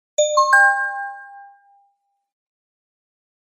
News-Alert04-1-C.ogg